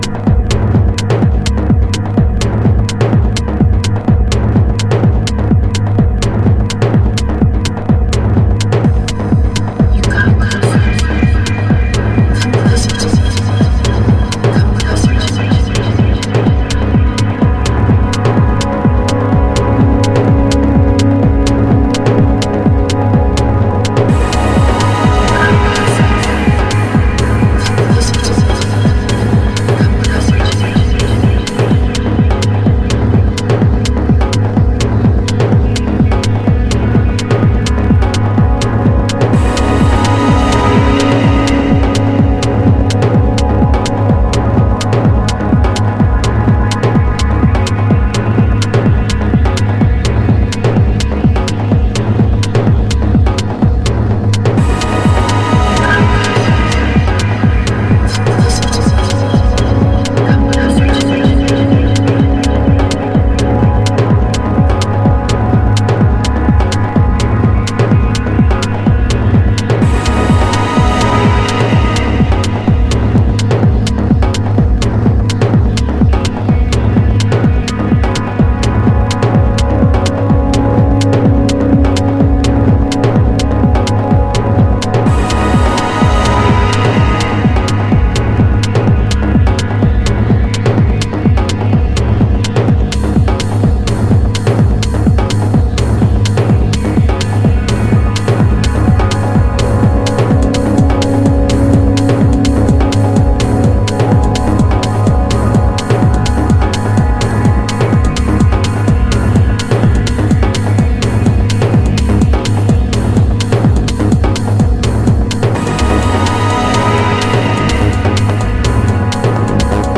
信頼のパッドシンセワークは健在。
House / Techno